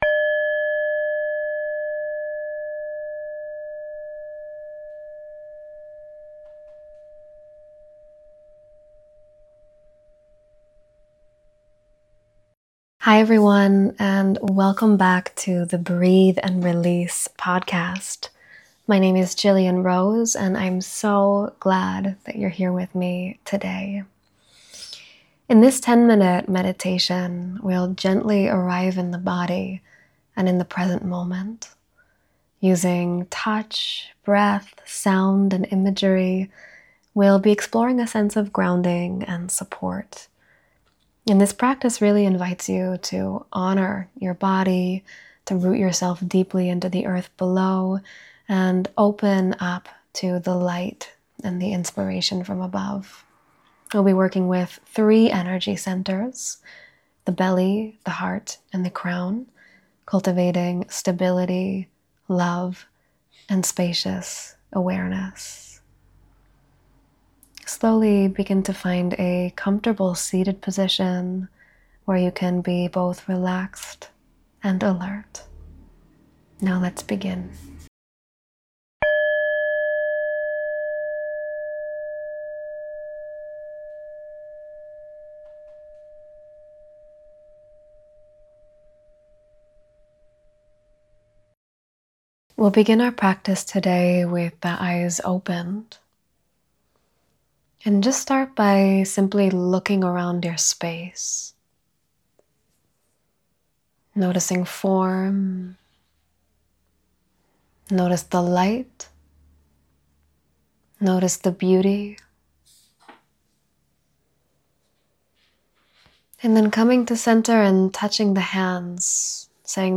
Connect deeply to both earth and sky in this grounding meditation practice.